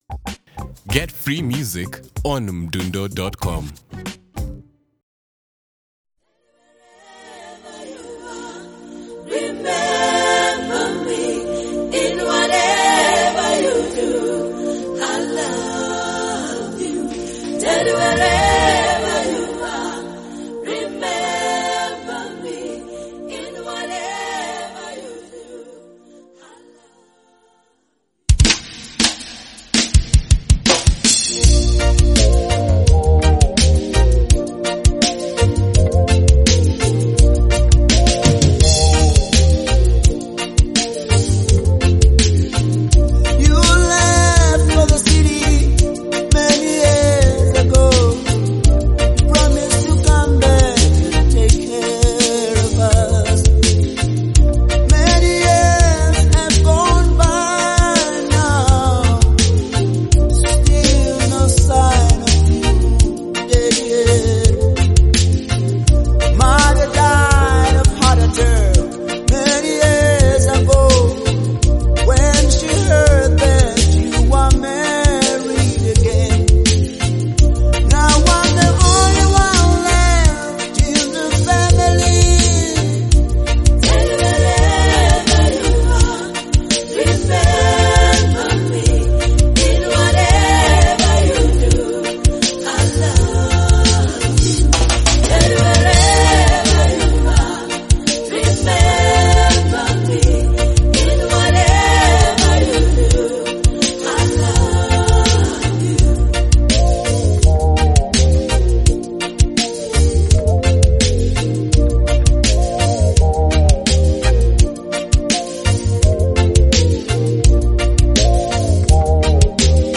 roots reggae song